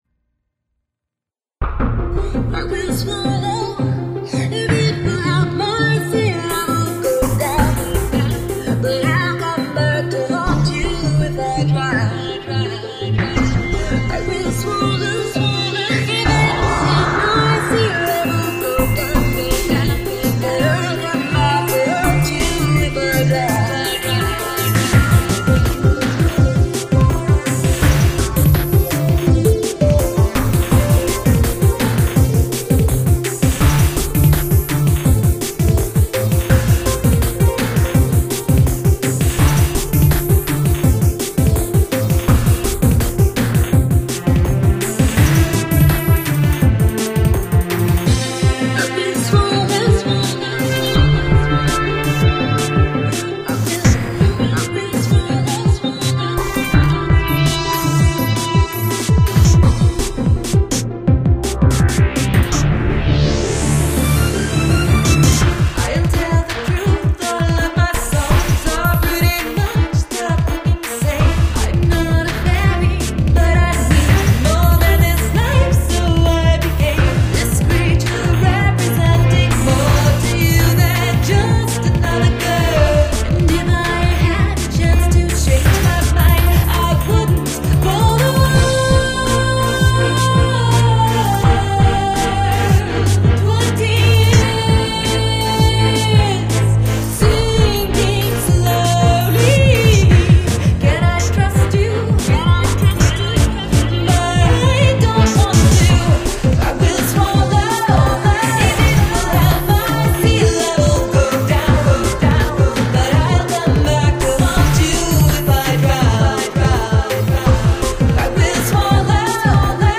голос+скрипка,ну и мягкие биты немного.расслабляет